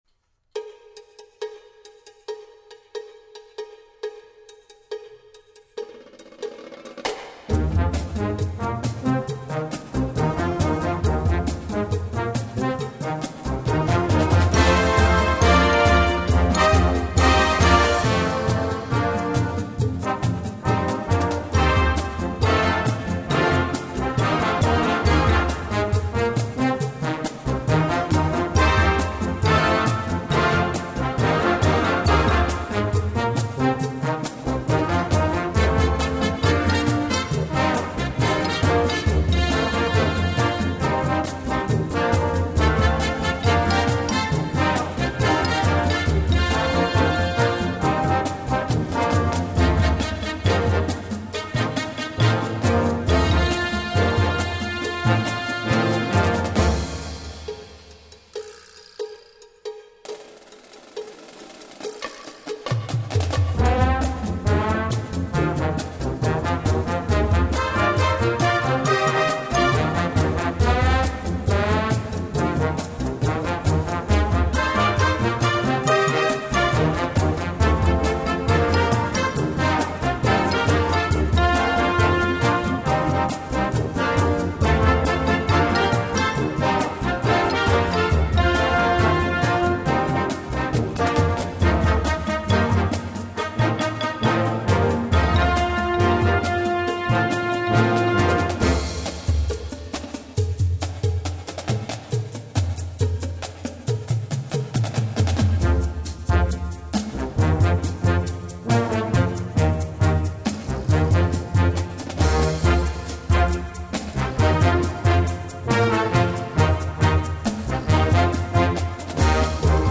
Gattung: Filmmusik
Marching-Band
Besetzung: Blasorchester